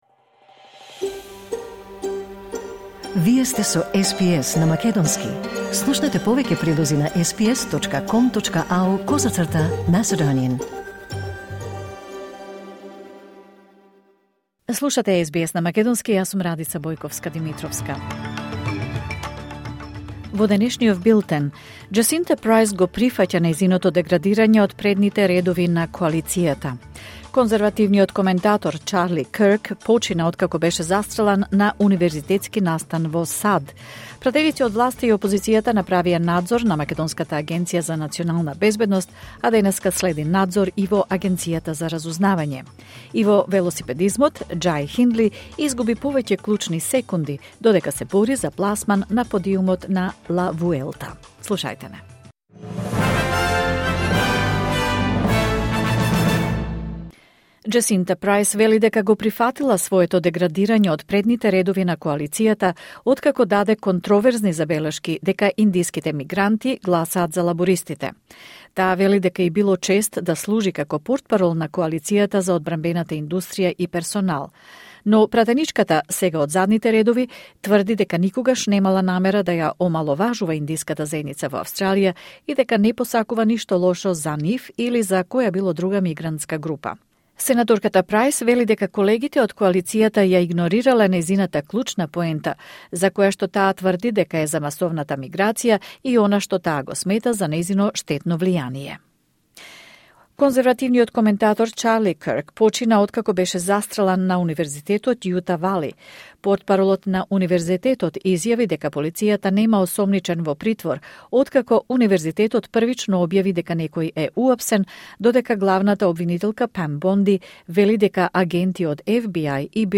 Вести на СБС на македонски 11 септември 2025